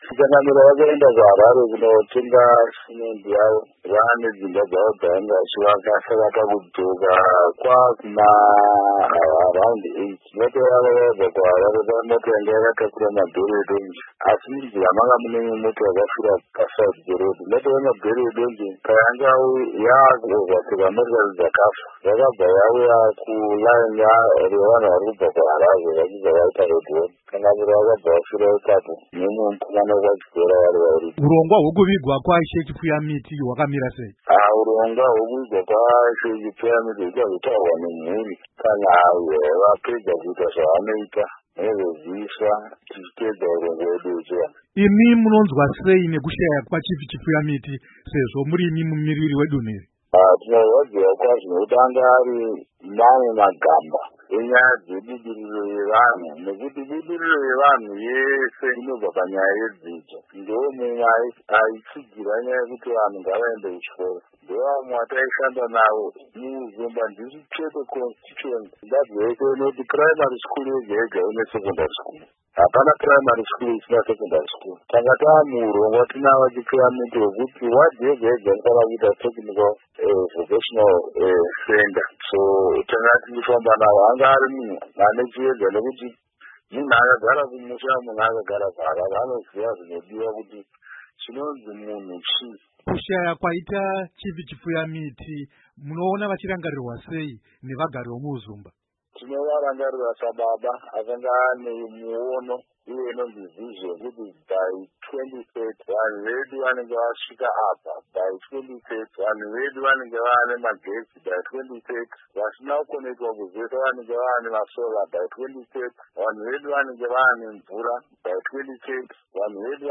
Hurukuro naVaSimba Mudarikwa